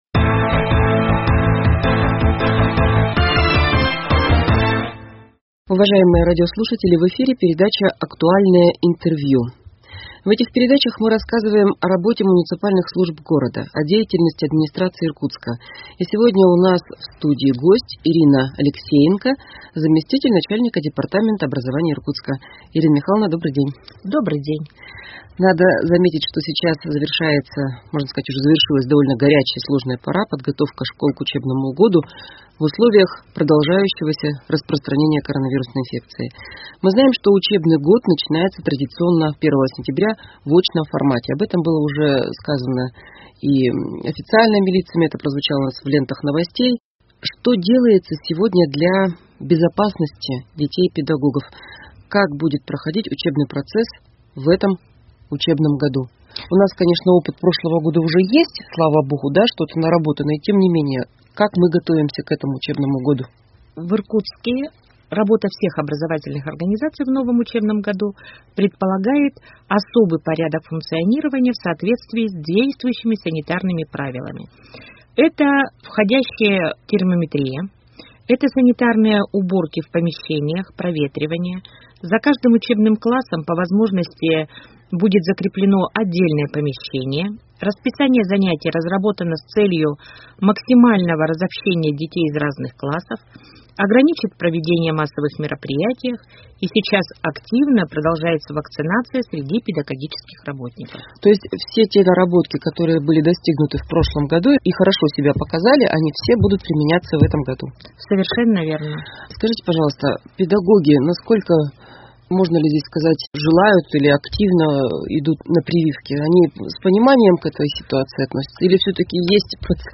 Актуальное интервью: Подготовка школ 30.08.2021